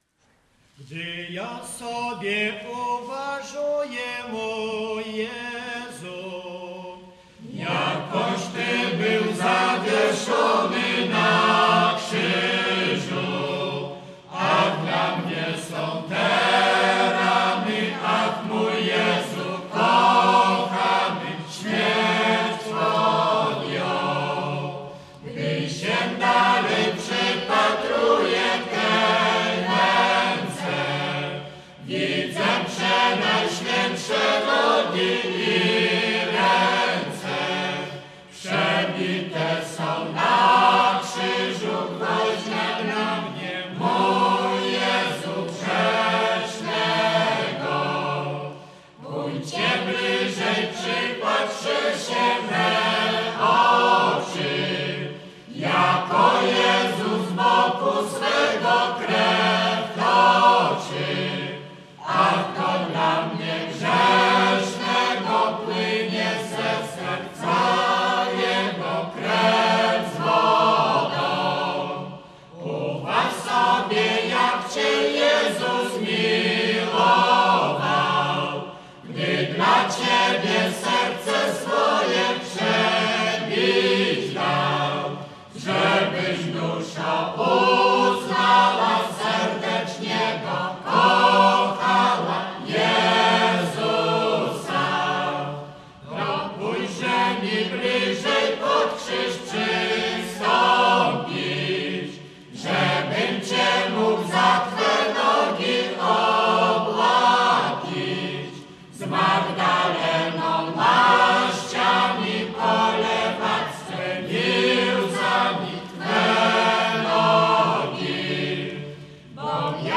Koncert Zespo�u "Pogranicze" podczas Festiwalu Gorzkich �ali w Warszawie 18.03.2010